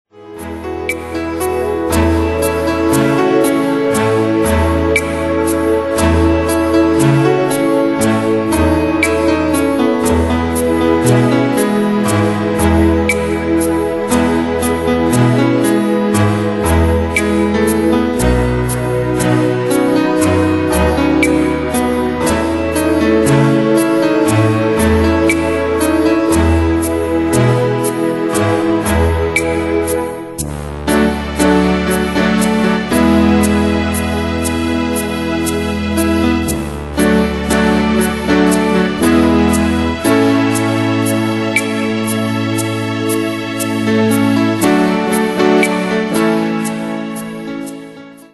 Demos Midi Audio
Danse/Dance: Ballade Cat Id.